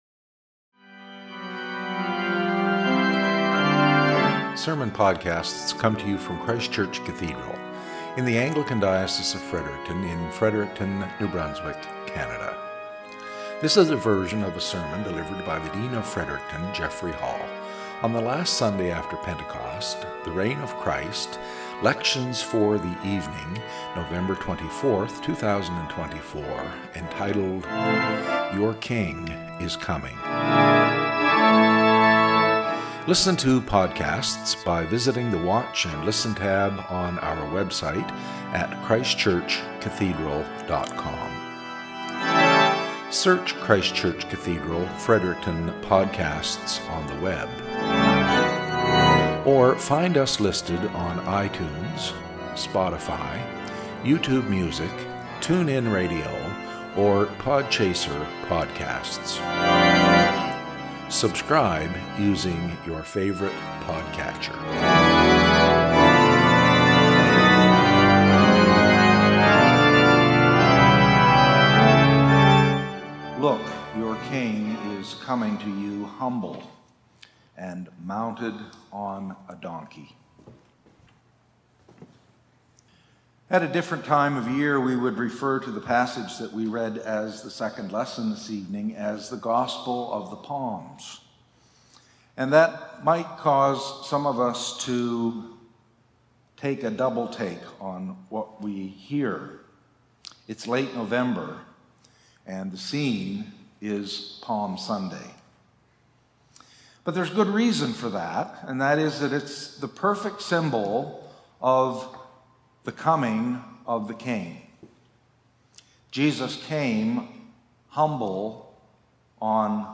SERMON - "Your King Is Coming to You"
Lections for Evensong: Last After Pentecost: The Reign of Christ (Proper 34)